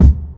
1 Kick -Grr.wav